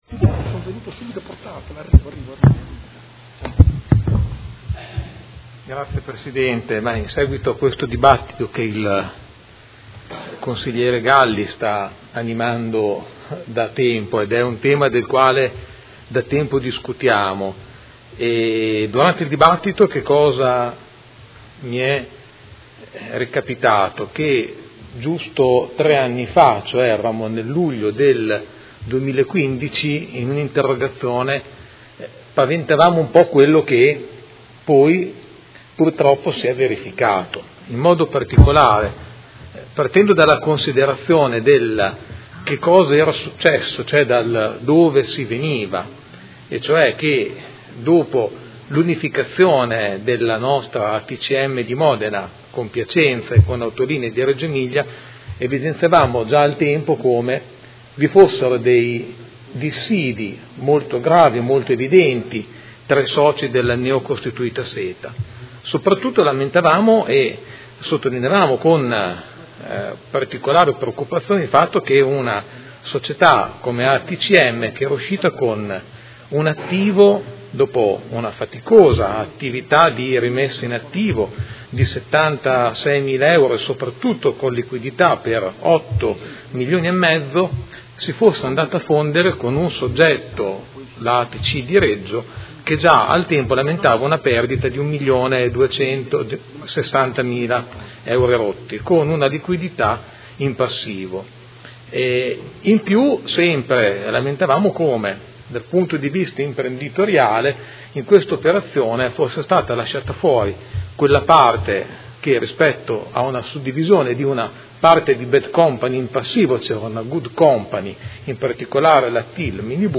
Seduta del 21/06/2018 Dibattito. Delibera nr. 84798 Convenzione tra i Soci pubblici modenesi di SETA S.p.A. - Approvazione